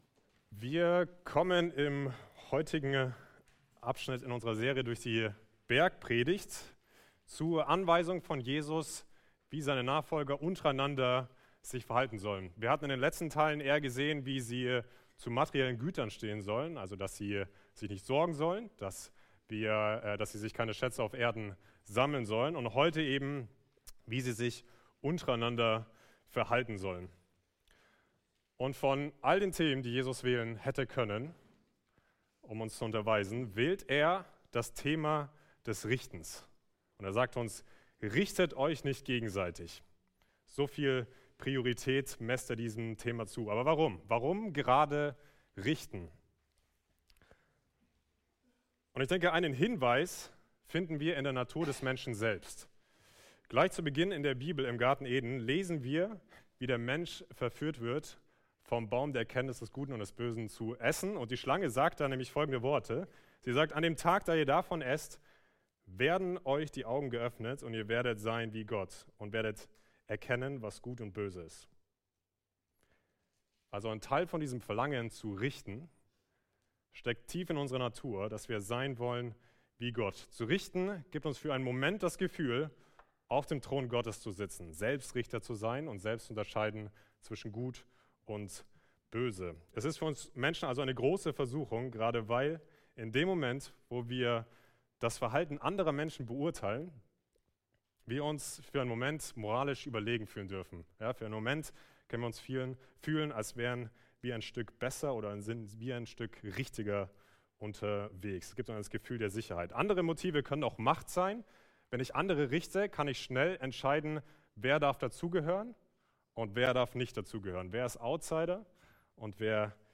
FeG München Mitte Predigt Podcast